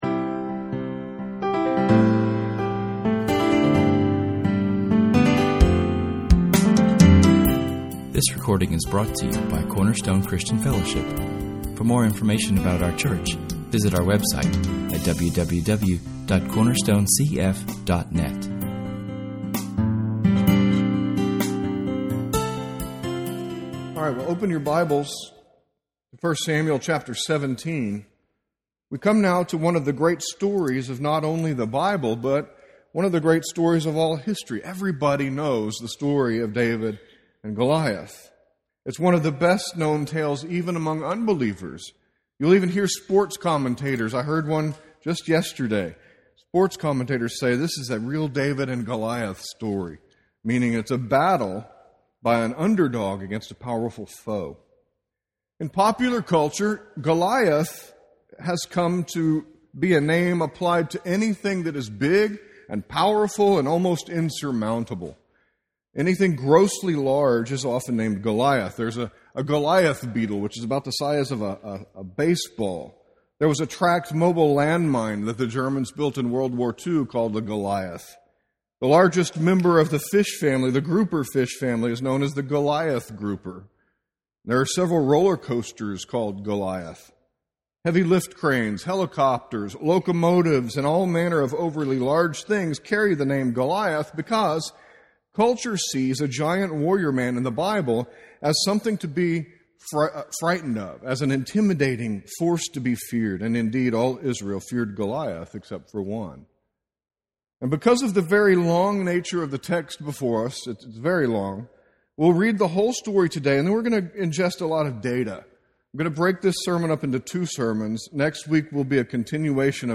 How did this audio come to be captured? Our message begins with a reading of the entire story told in [esvignore]1 Samuel 17[/esvignore]. This tale is one of great typology and serves to point us to so many gospel truths that we cannot cover them all in one message, and two sermons will only hit the high points.